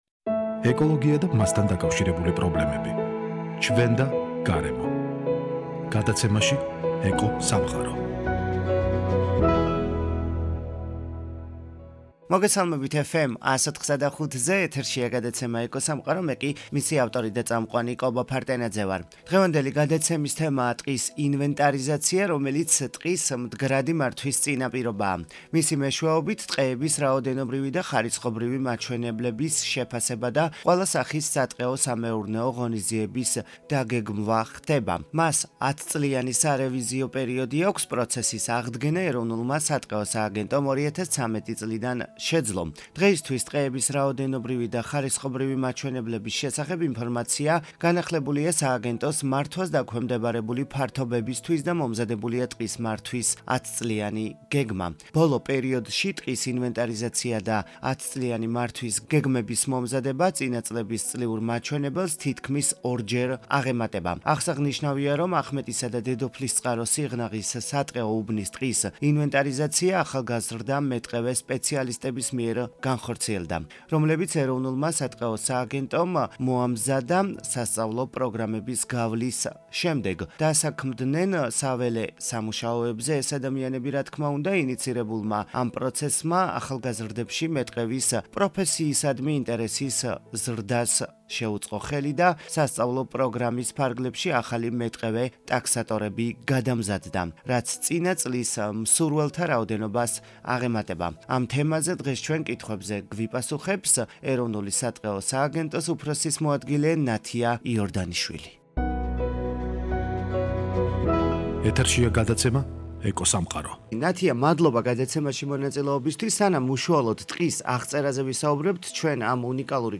გადაცემაში ამ თემაზე კითხვებზე გვპასუხობს, ეროვნული სატყეო სააგენტოს უფროსის მოადგილე -  ნათია იორდანიშვილი.